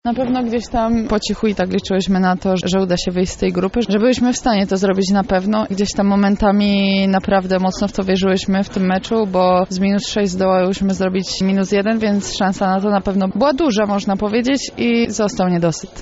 • mówiła po zakończeniu spotkania rozgrywająca ekipy z Lublina